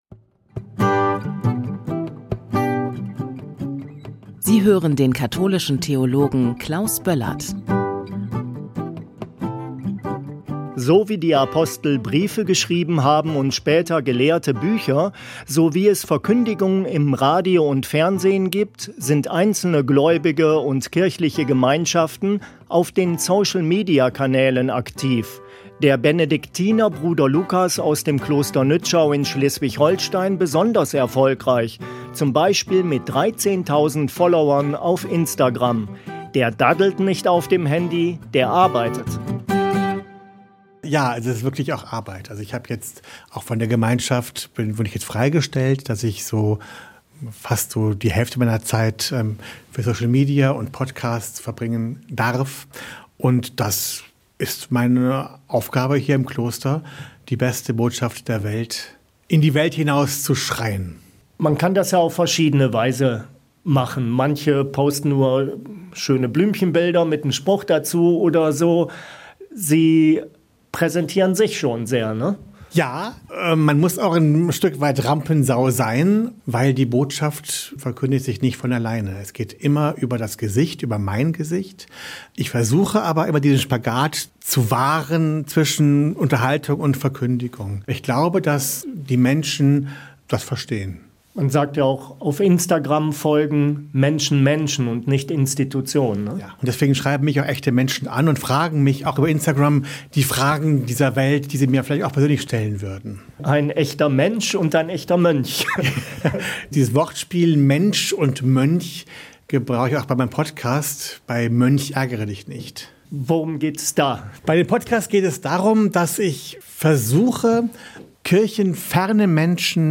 der Benediktinermönch